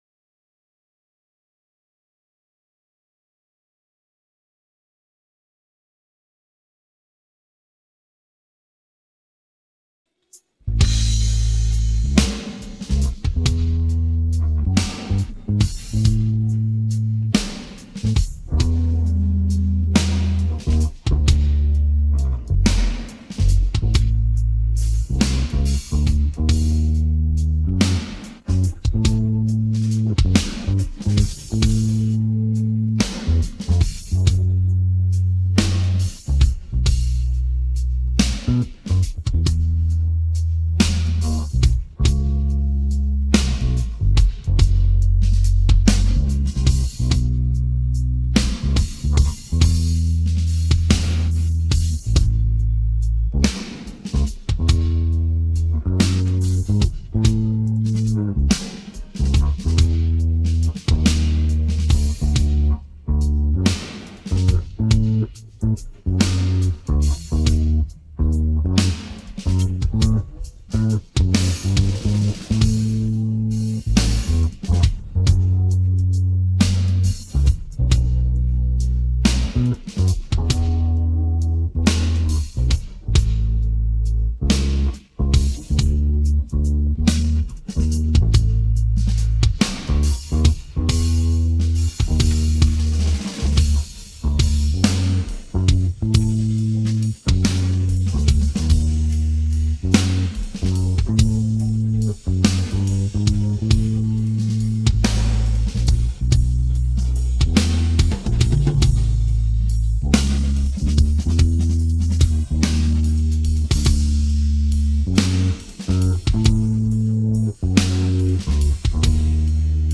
Here's the tune without guitar
There is a 10 second silence at the start